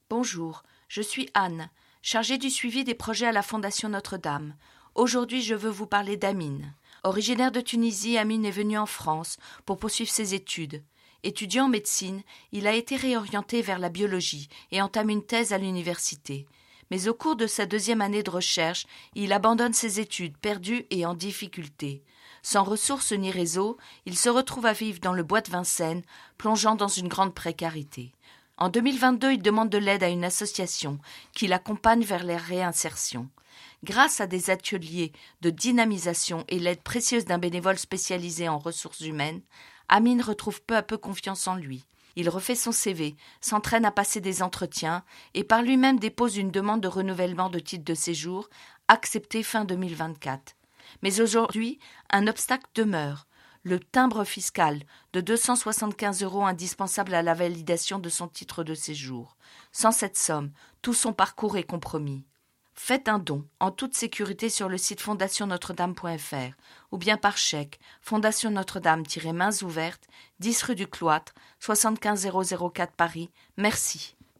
Messages « Mains Ouvertes » sur Radio Notre Dame